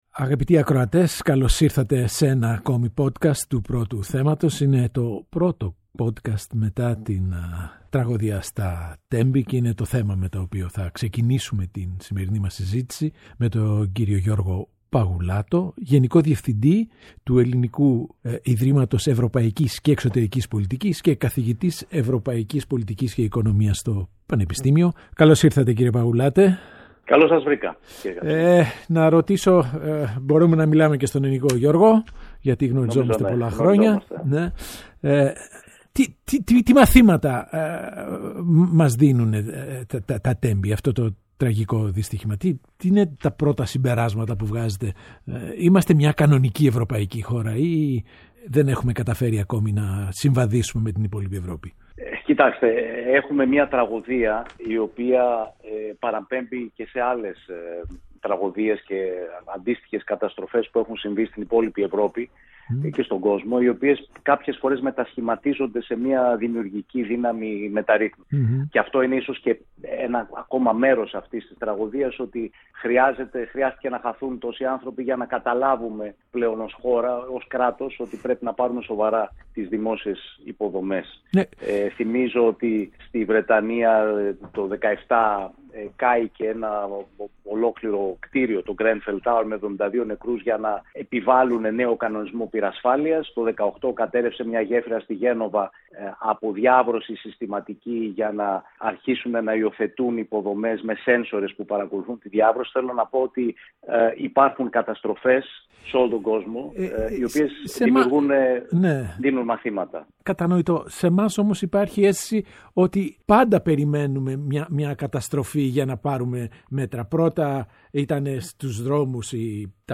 O Παντελής Καψής συζητά με τον Γιώργο Παγουλάτο: Τα μαθήματα από τα Τέμπη